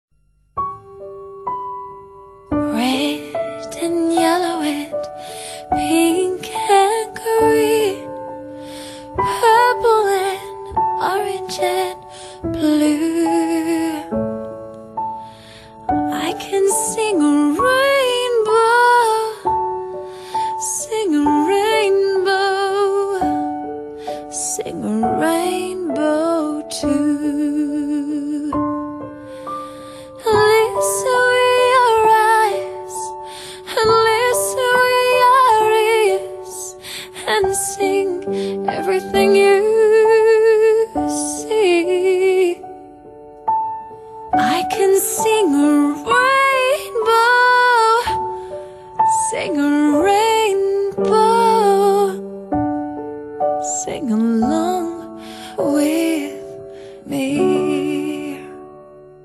纯净的女声搭配画面优美的MV，